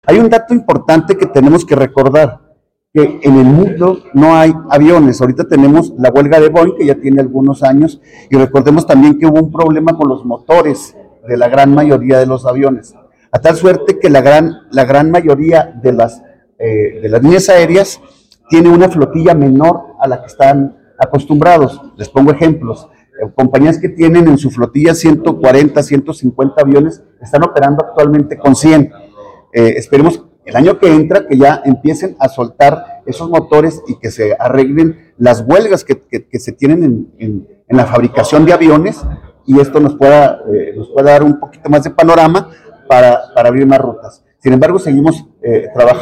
AUDIO: EDIBRAY GÓMEZ, SECRETARIO ESTATAL DE TURISMO 2